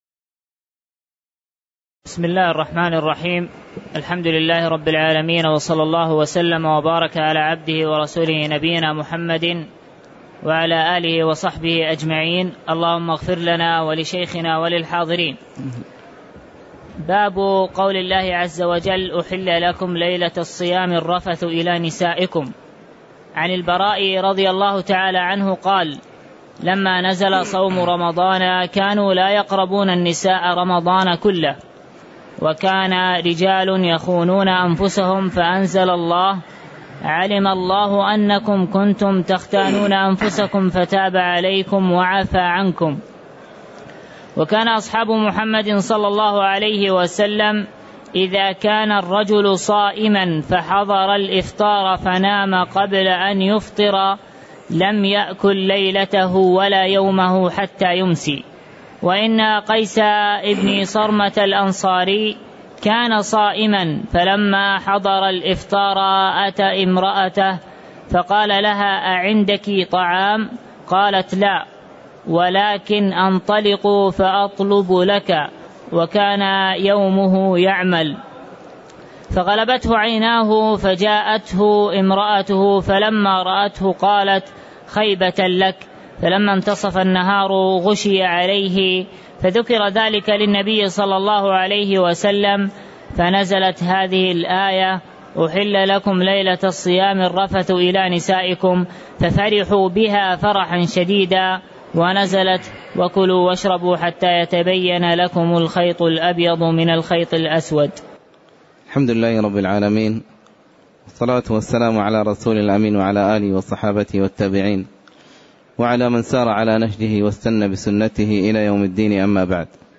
تاريخ النشر ١٩ شعبان ١٤٣٧ هـ المكان: المسجد النبوي الشيخ